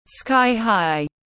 Cao chọc trời tiếng anh gọi là sky-high, phiên âm tiếng anh đọc là /ˌskaɪ haɪ/ .
Sky-high /ˌskaɪ haɪ/